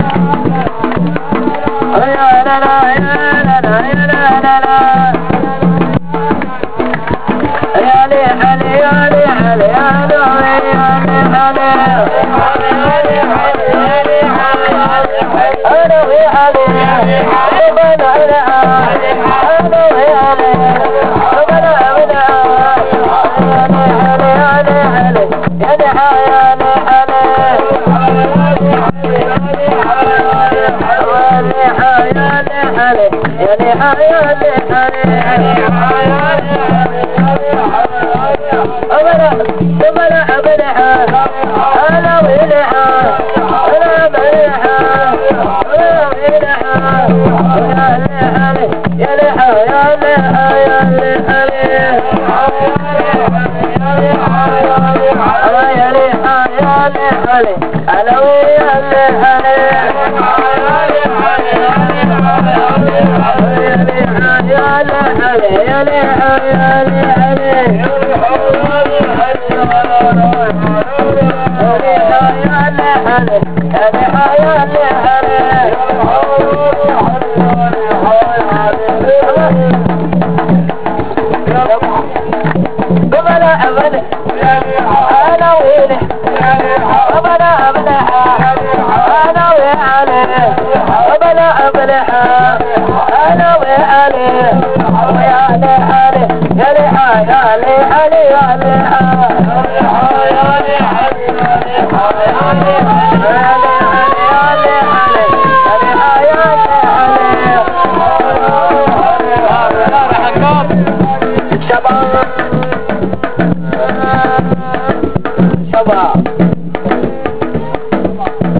تفضلو يا اخوان اهازيج للنادي الاهلي:
ثانيا اهازيج الجمهور ,قم بحفظها (save traget as):